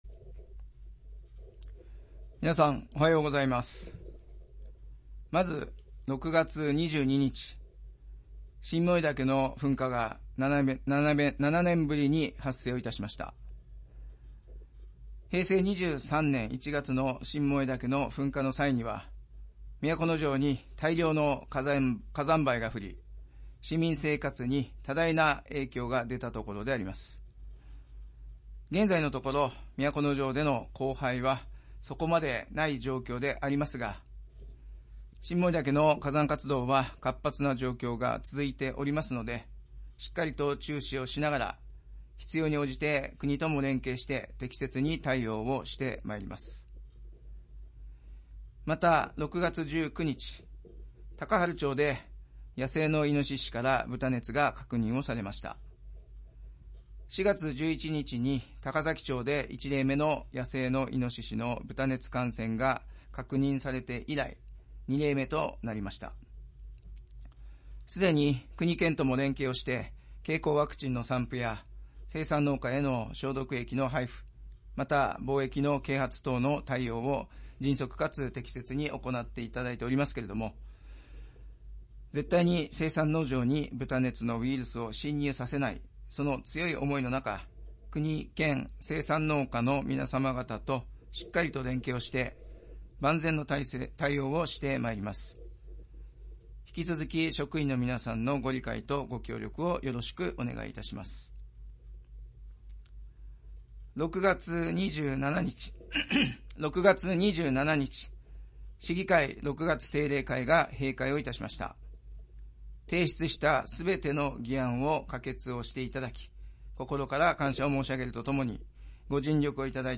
市長が毎月初めに行う職員向けの庁内メッセージを掲載します。